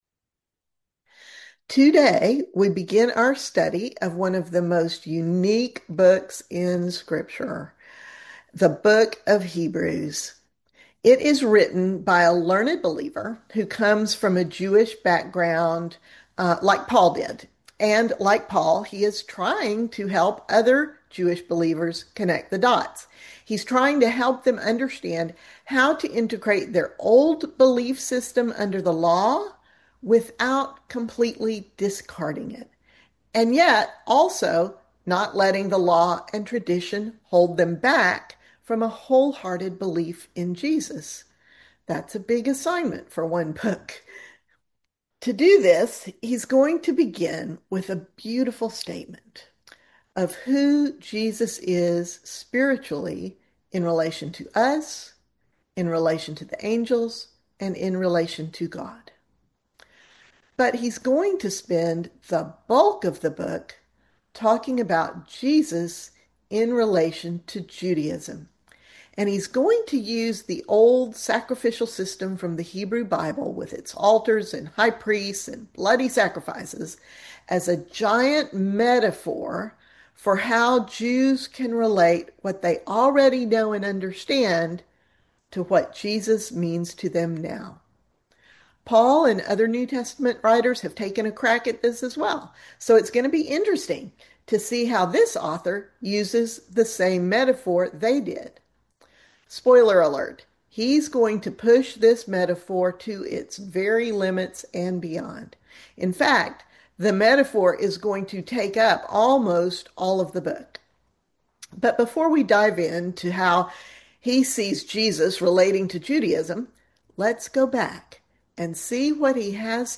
A Gentle Ramble Through the Bible - Class 176